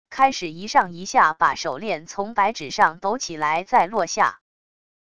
开始一上一下把手链从白纸上抖起来再落下wav音频